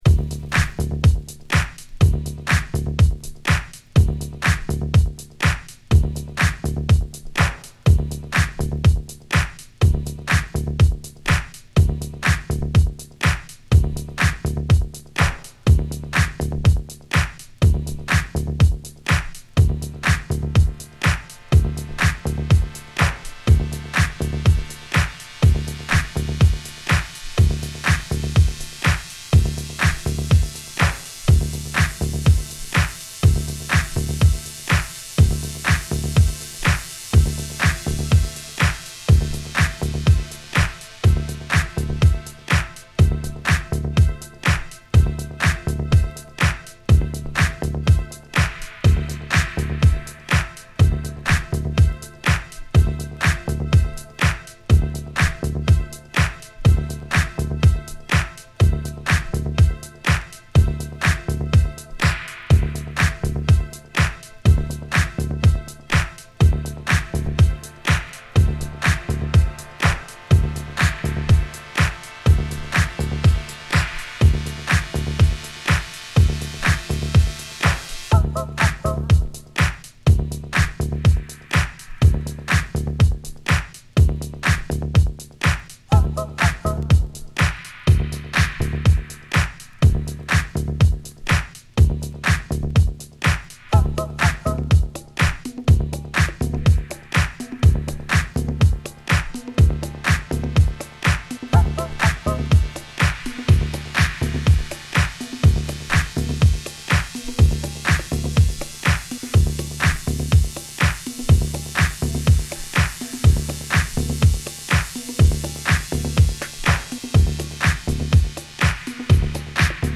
広がるシンセ音に鋭いダビーなエフェクトが交わり、徐々に盛り上がっていくスペース・ディスコ・ダブ！
はゆったり踊れるディスコダブに。
＊試聴はA→B1→B2です。